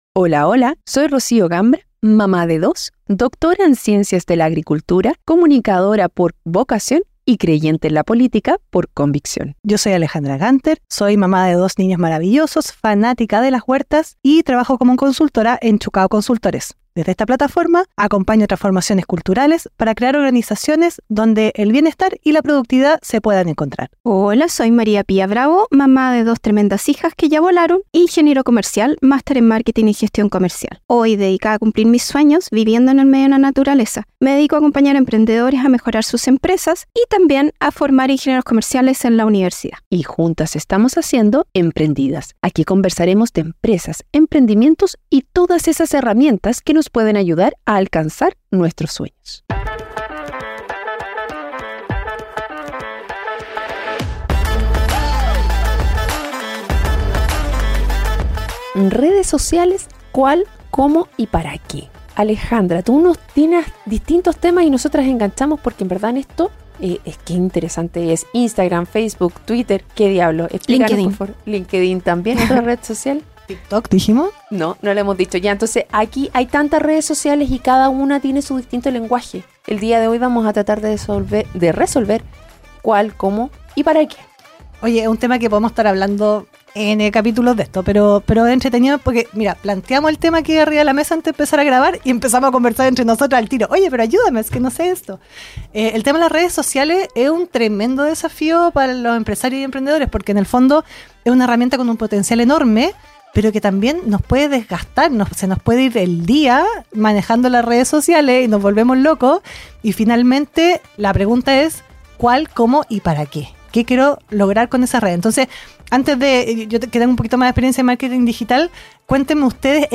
🔵Somos tres amigas